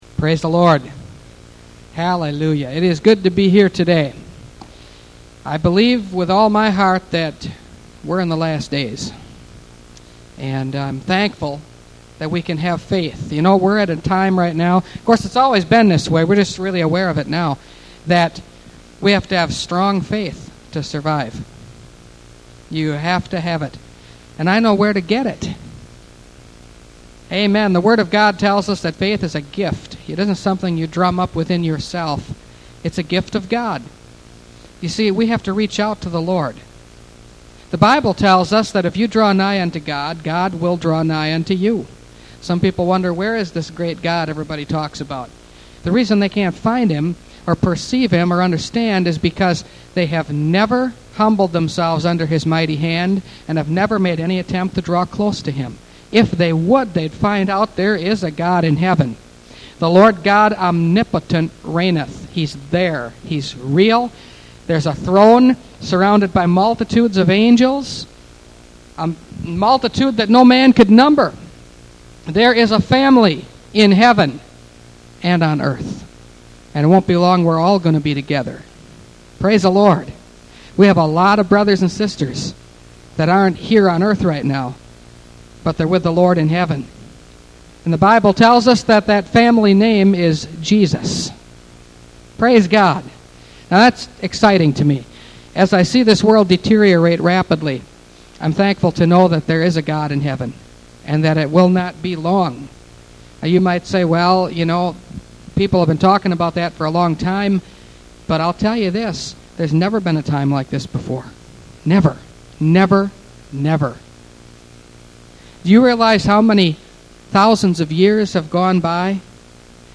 Revelation Series – Part 38 – Last Trumpet Ministries – Truth Tabernacle – Sermon Library